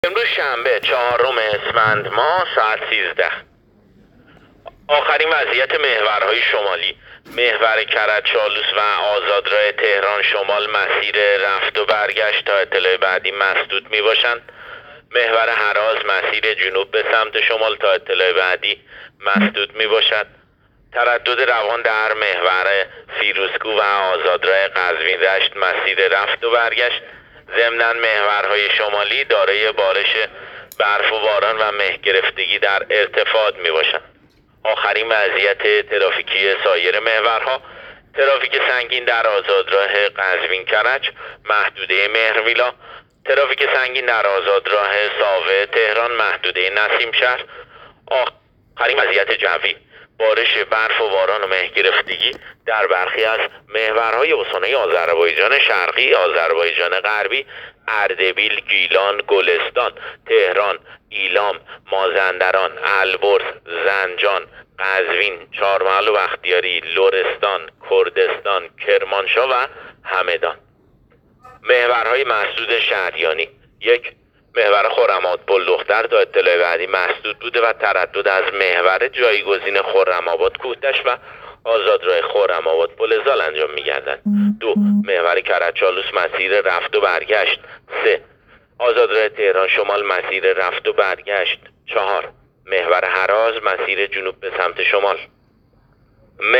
گزارش رادیو اینترنتی از آخرین وضعیت ترافیکی جاده‌ها ساعت ۱۳ چهارم اسفند؛